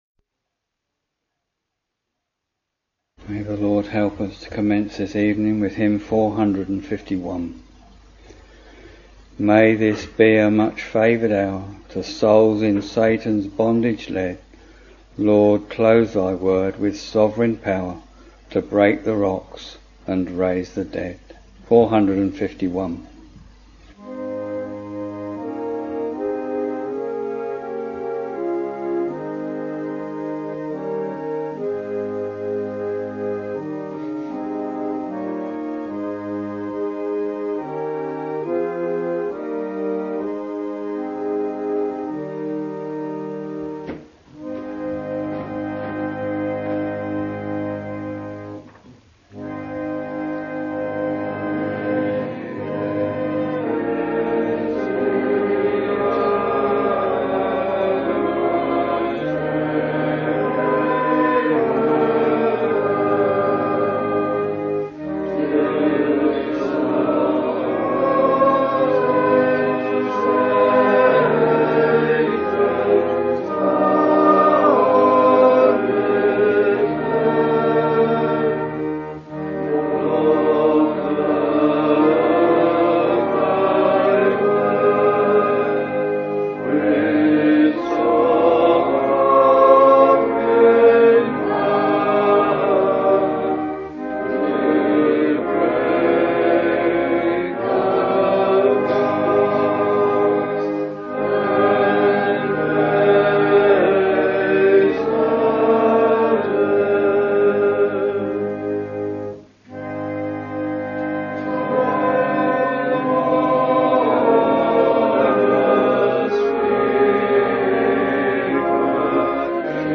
Week Evening Service